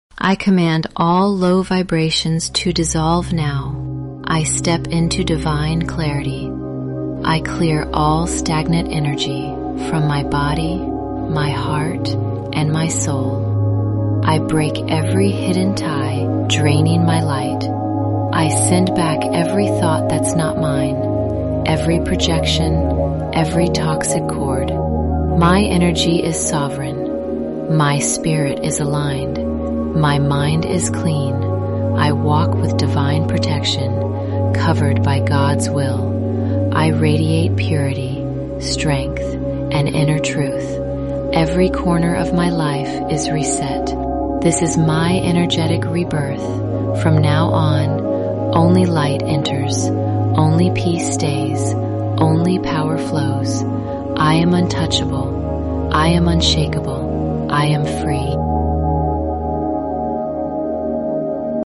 Play this and let every toxic tie, every shadow, every stagnant vibe dissolve. 417 Hz + 741 Hz for a full soul reset.